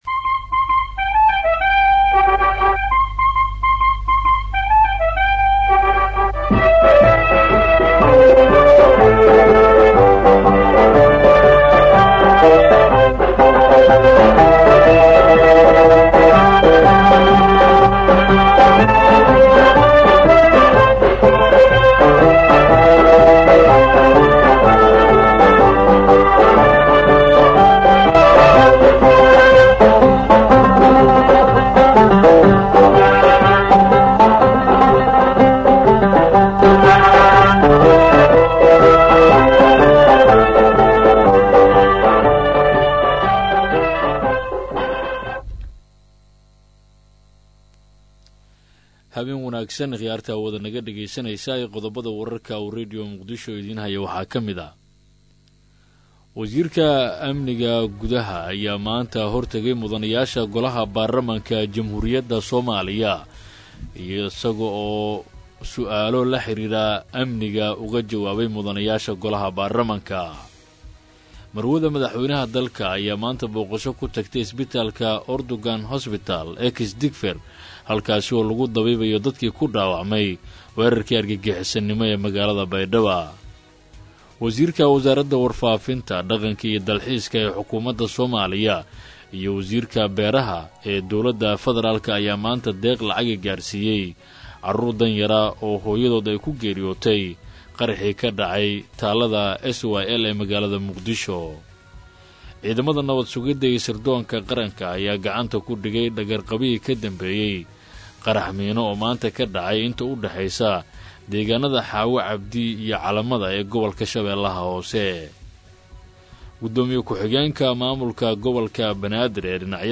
WARKA HABEEN -1-3-2016